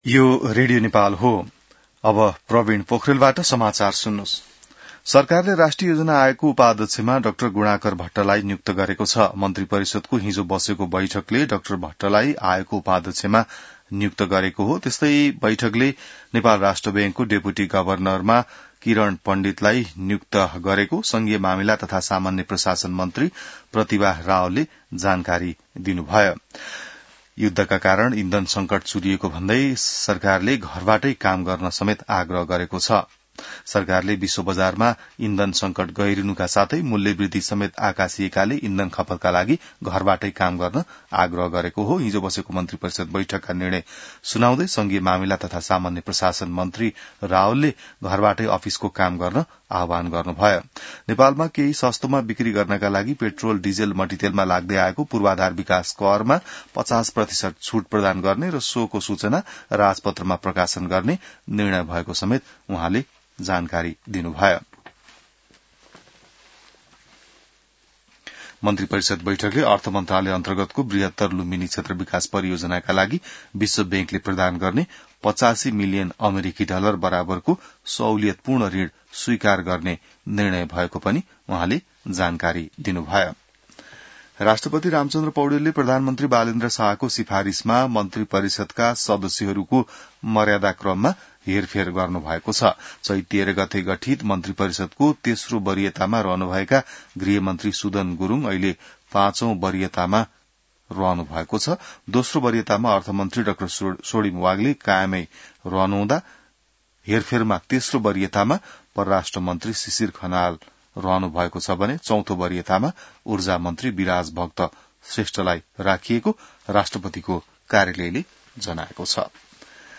An online outlet of Nepal's national radio broadcaster
बिहान ६ बजेको नेपाली समाचार : २५ चैत , २०८२